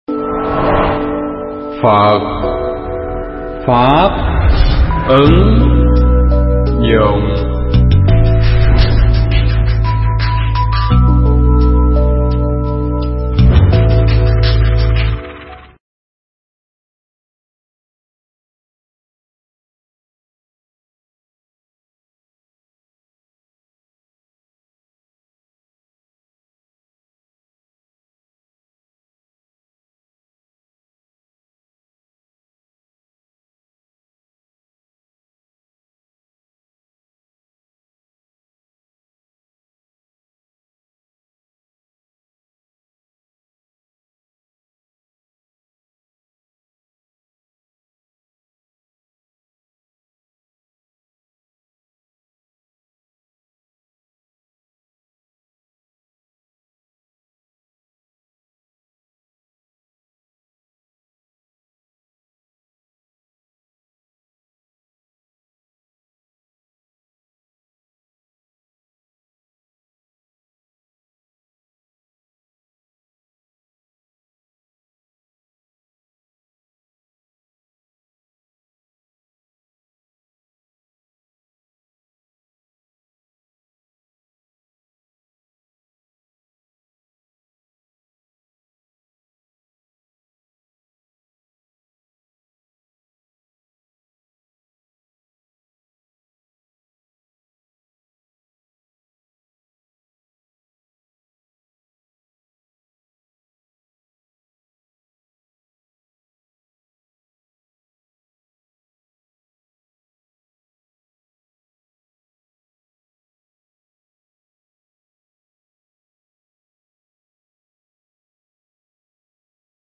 Mp3 Thuyết pháp Tại Sao Chúng Ta Sợ Chết